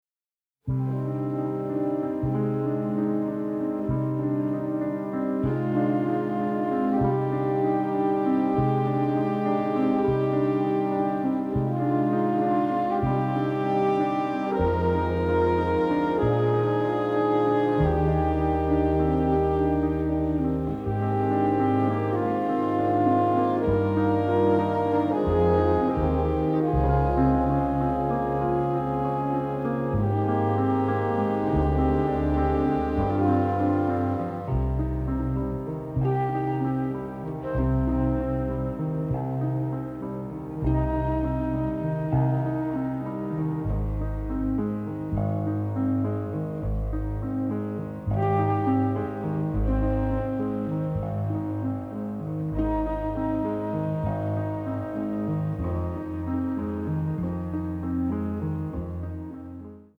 romantic, innovative, masterful score